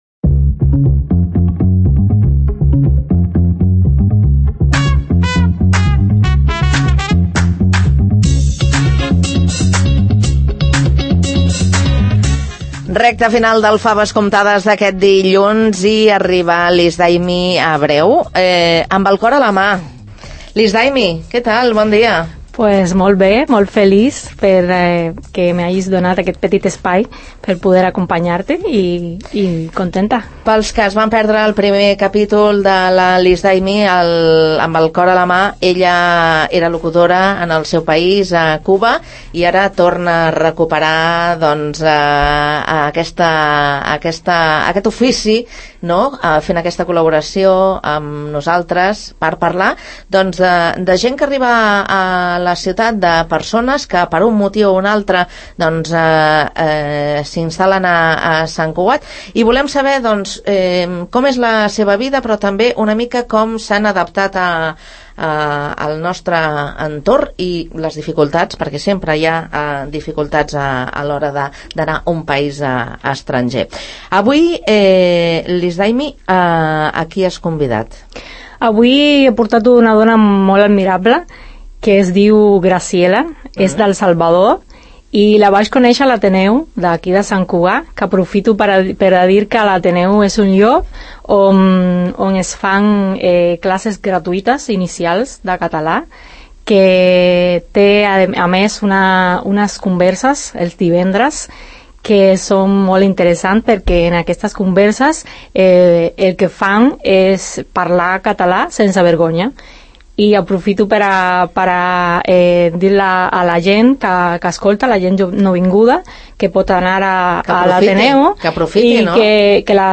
una conversa al magazín radiofònic ‘Faves comptades